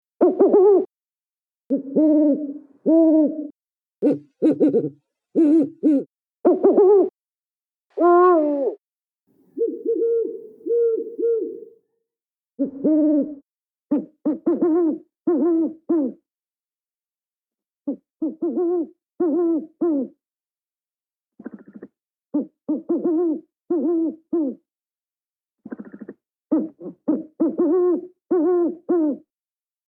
Звуки совы